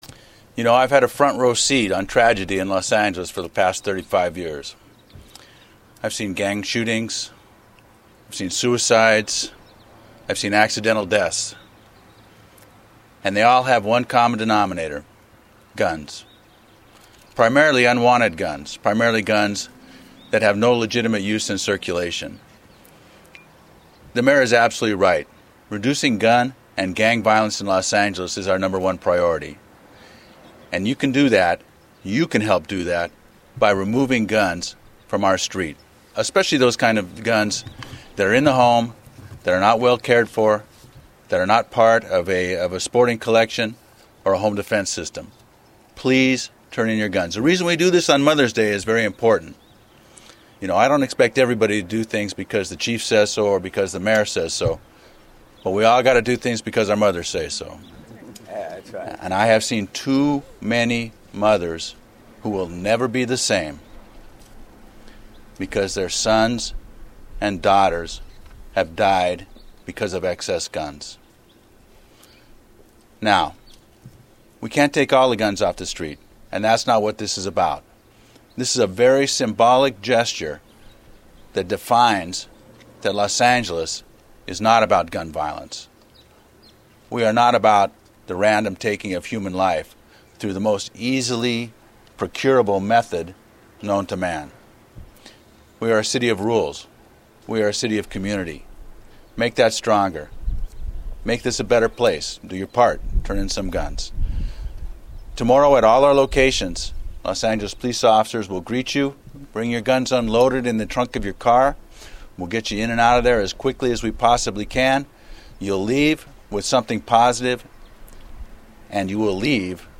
Click above to hear Chief Beck discuss the program.